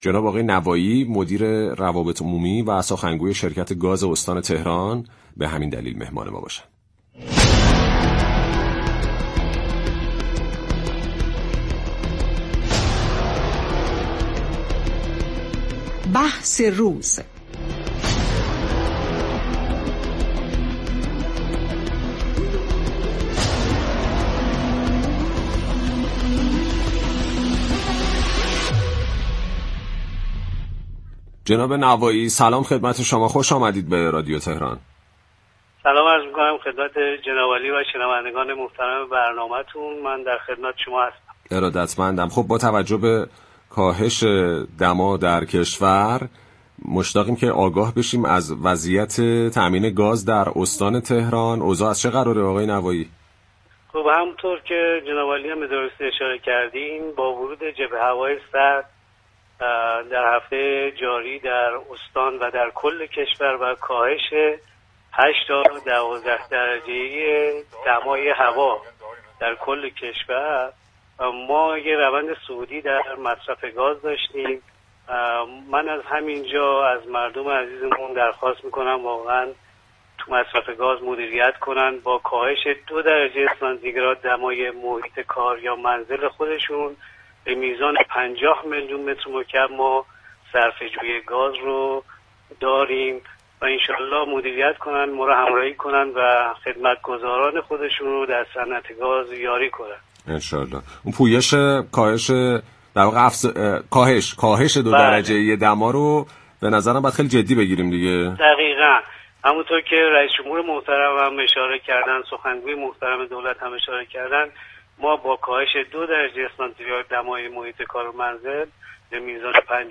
در گفت و گو با «بازار تهران»